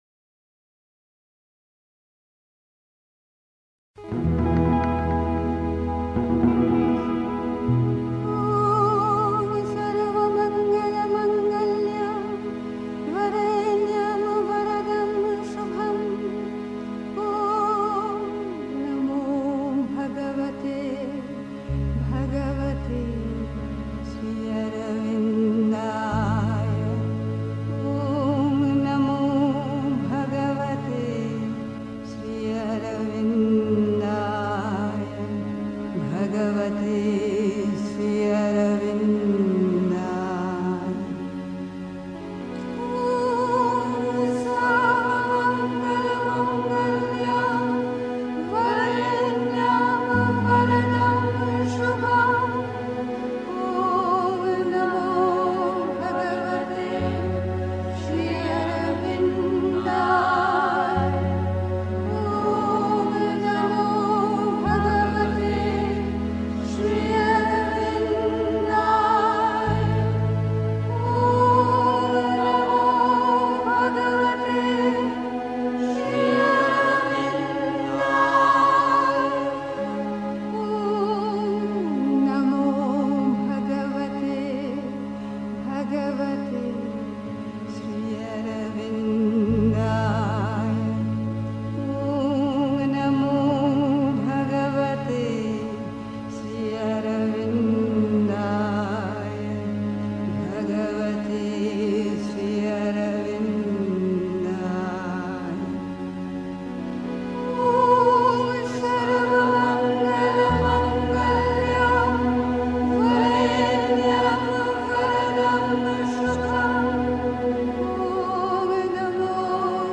1. Einstimmung mit Musik. 2. Das Übernatürliche (Sri Aurobindo, Thoughts and Aphorims, 84.-86.) 3. Zwölf Minuten Stille.